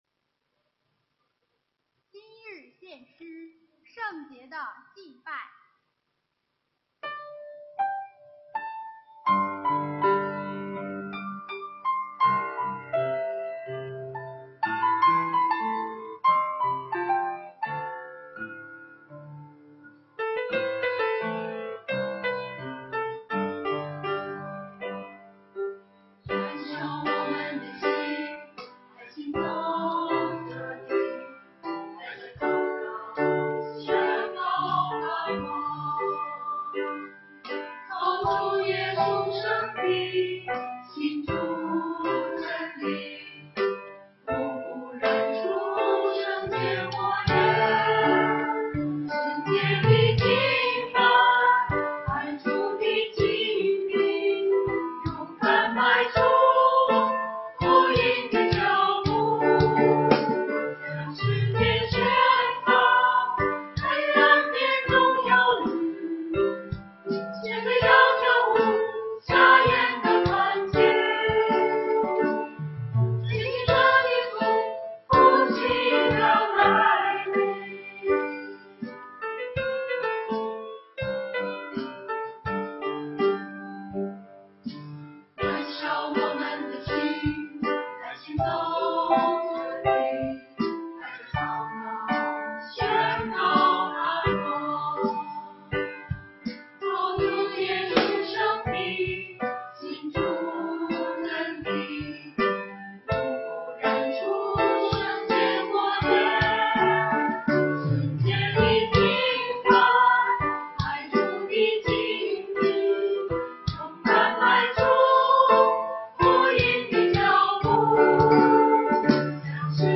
[2022年09月04日主日献唱]《圣洁的敬拜》 | 北京基督教会海淀堂
团契名称: 联合诗班 新闻分类: 诗班献诗 音频: 下载证道音频 (如果无法下载请右键点击链接选择"另存为") 视频: 下载此视频 (如果无法下载请右键点击链接选择"另存为")